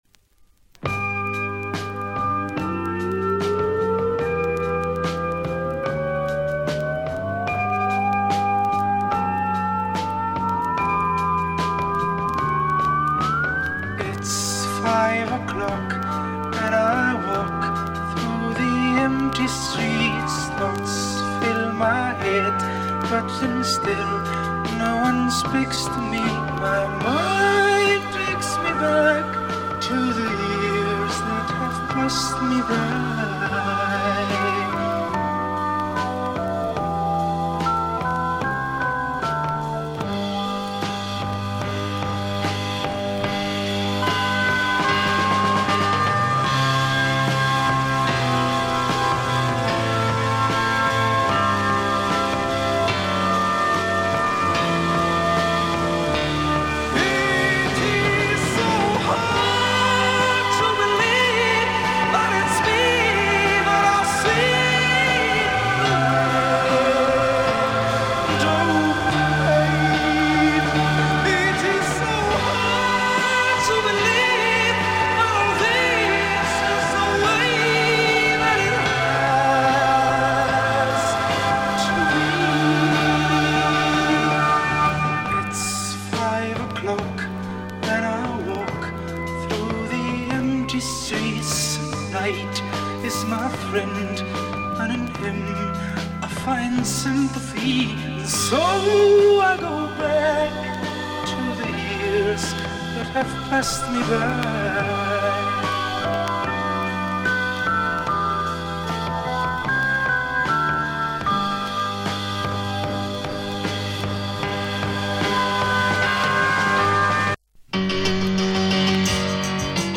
Жанр: PSYCH